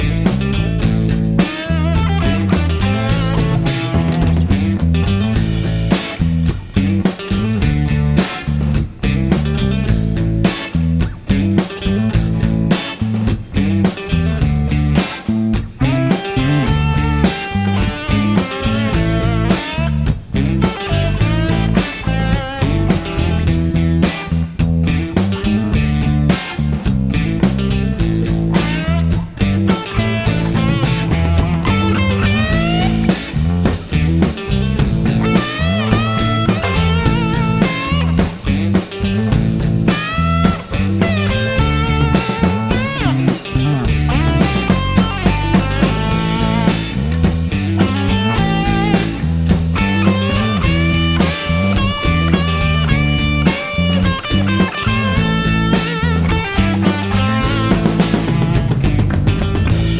no Backing Vocals Blues 2:47 Buy £1.50